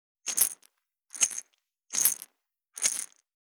３７１調味料固形物,カシャカシャ,サラサラ,パラパラ,
効果音厨房/台所/レストラン/kitchen